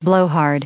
added pronounciation and merriam webster audio
112_blowhard.ogg